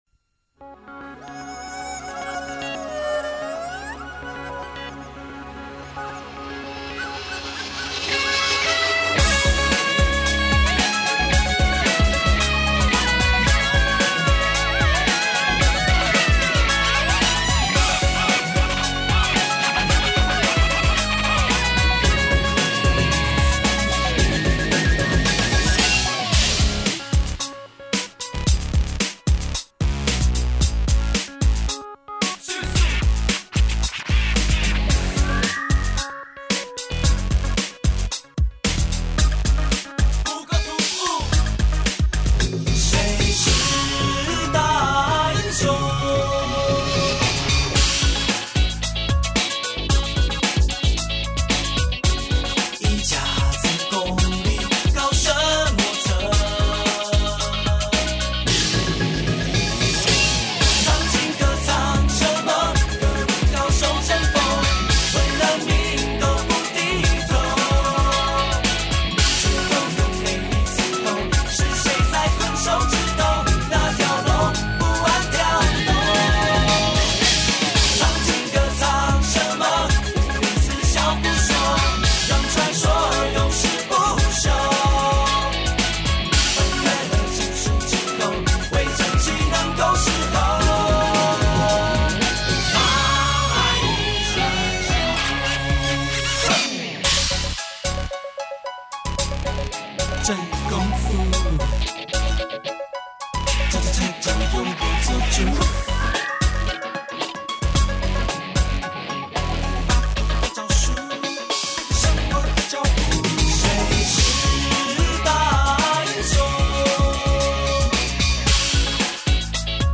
电吉他伴奏